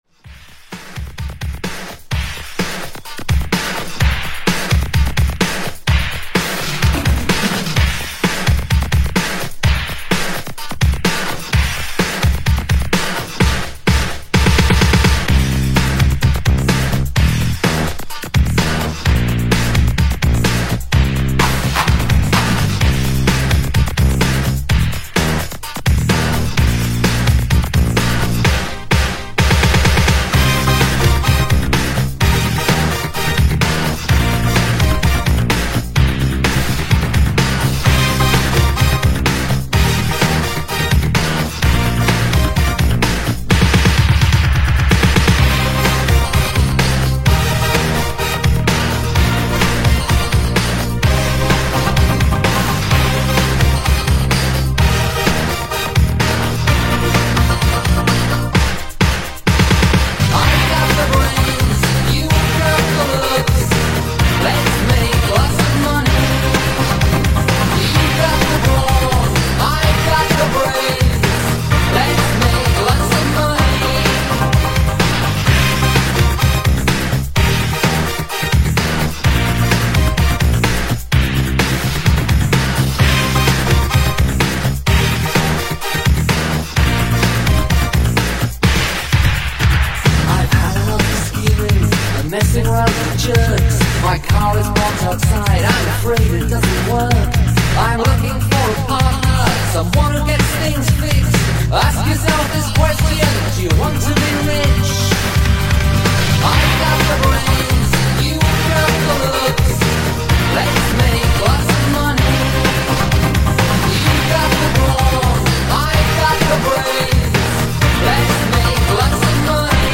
[121 BPM]